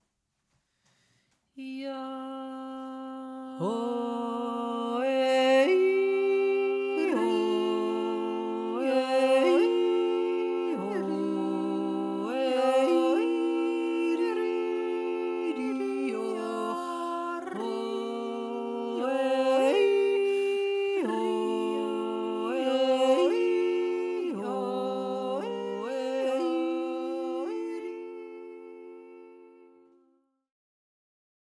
Ein gehaltener Ton - Bordun (V)
schladmingerkuahtreiber-mit-bordun.mp3